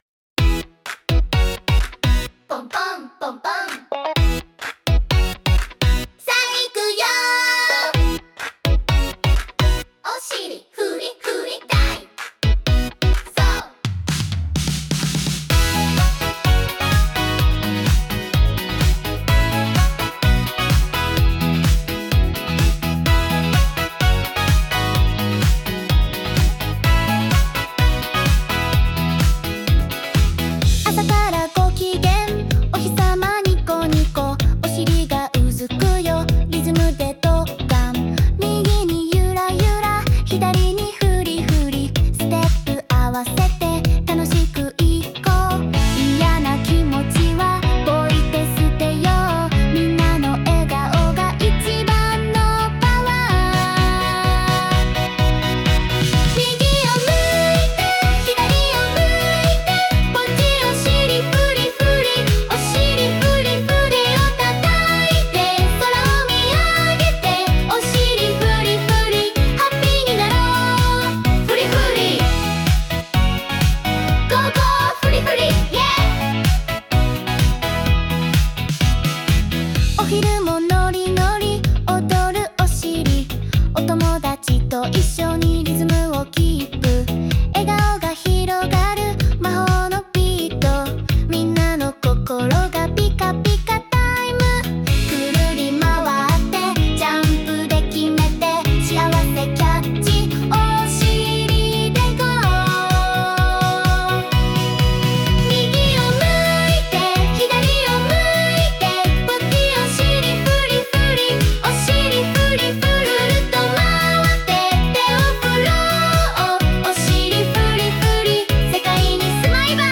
まず、これらの曲に込められた魅力はなんといっても、そのリズミカルでパーティ感あふれる雰囲気です。
みんなで踊れる覚えやすいサビにアレンジしました。
作曲：最新AI